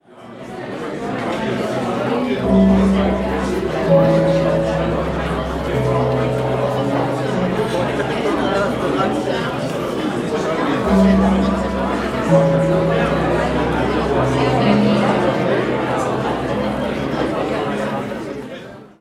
Ein Gong bat und riet zur Aufmerksamkeit für ein spannendes Programm (Audio 1/11) [MP3]
Der Ort des Geschehens: das „forum M“